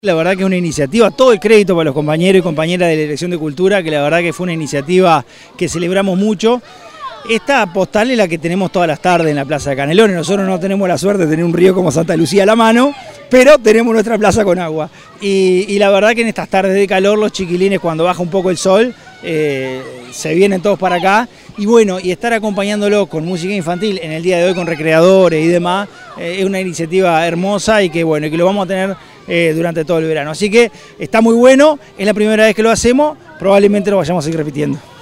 francisco_legnani_secretario_general_6.mp3